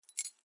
声音效果" keys < Cs G>
描述：使用Zoom H2n录制。钥匙扣噪音